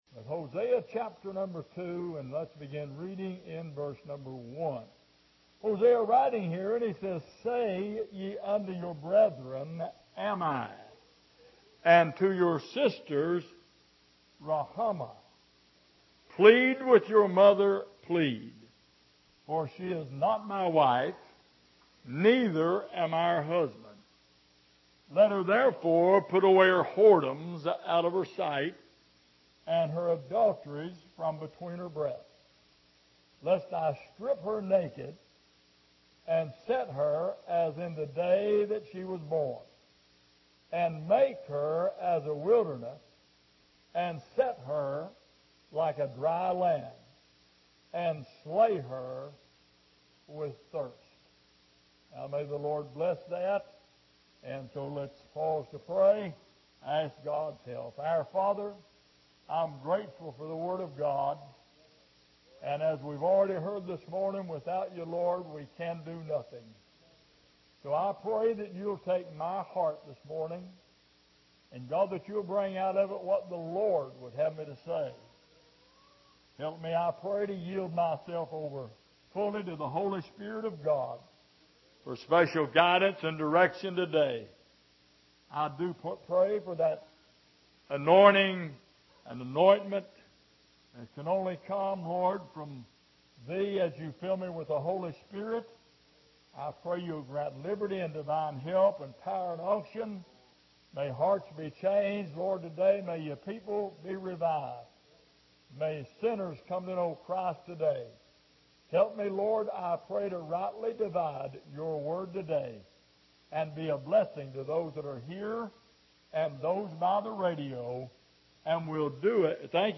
Passage: Hosea 2:1-3 Service: Sunday Morning